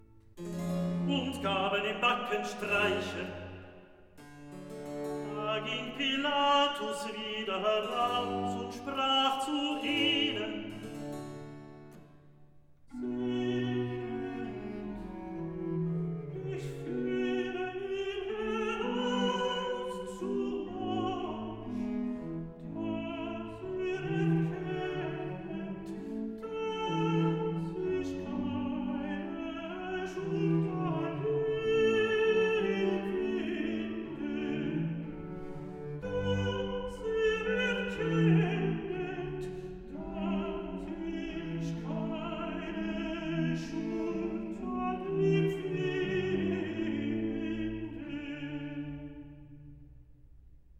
04 - Recitativo evangelist Und gaben ihm